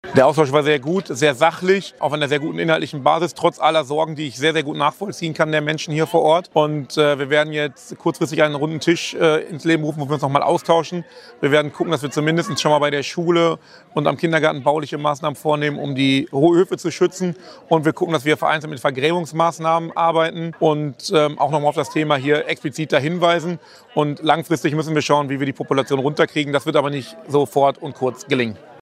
newston-dennis-rehbein-austausch-helfe-zu-wildschweinen.mp3